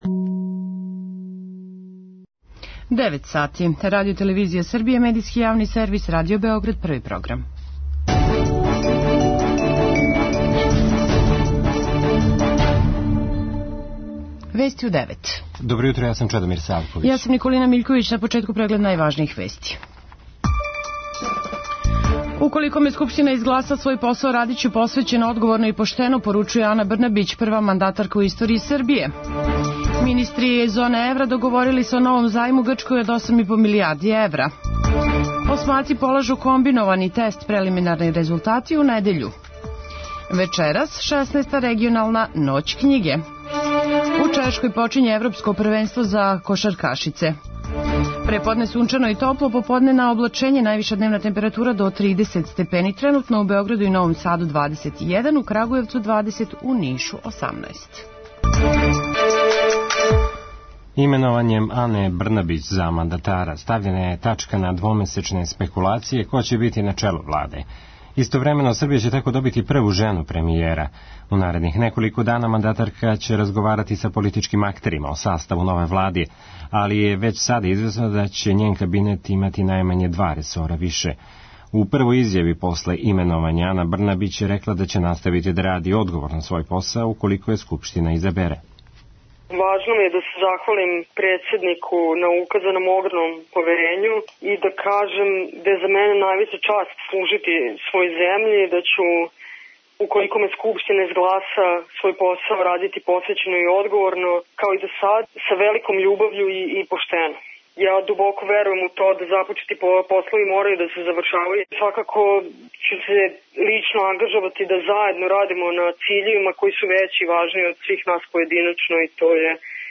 преузми : 3.79 MB Вести у 9 Autor: разни аутори Преглед најважнијиx информација из земље из света.